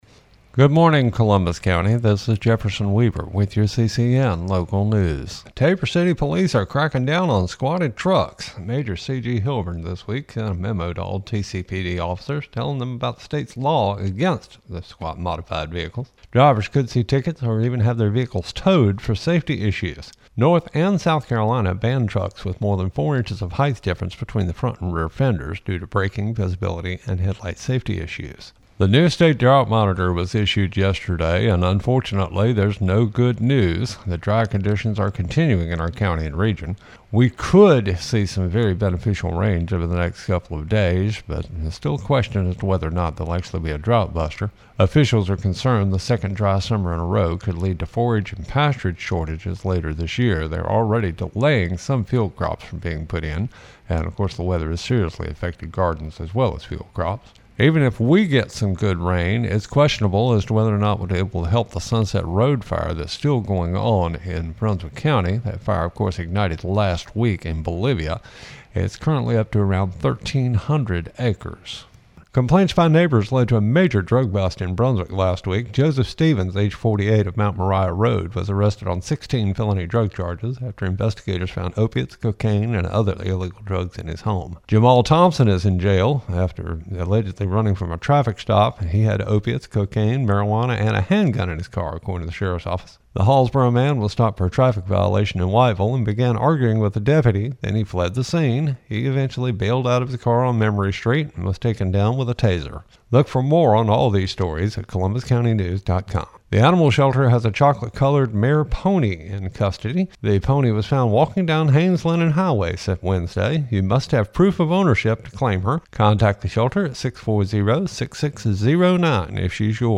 CCN Radio News — Morning Report for May 9, 2025
CCN-MORNING-NEWS-REPORT-2-1.mp3